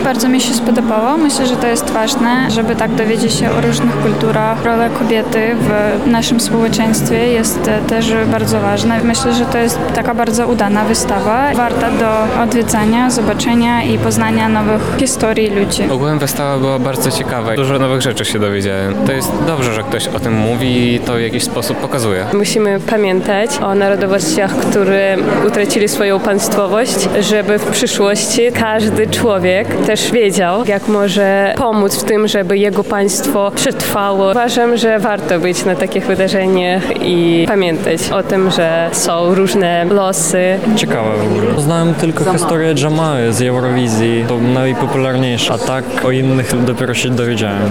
Ona Krym | Ona Świat, relacja
Opinie-widzow_01-1.mp3